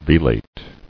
[ve·late]